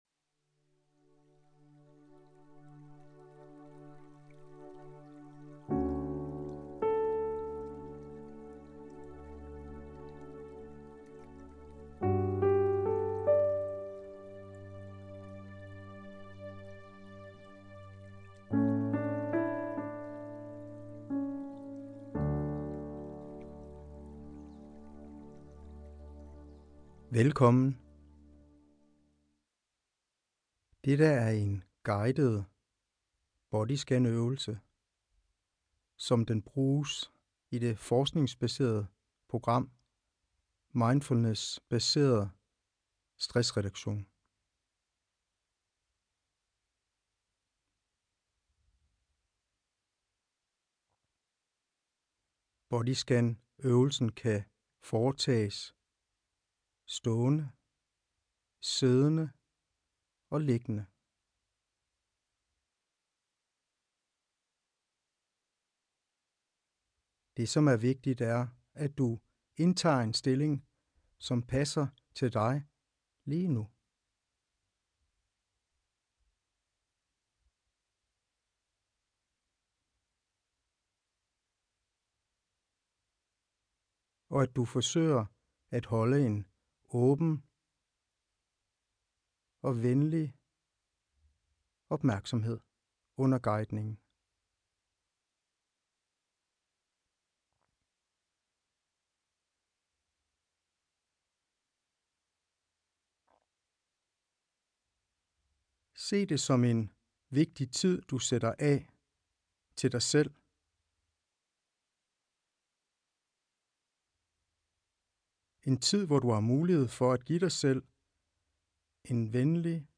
Body Scan liggende